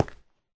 stone4.ogg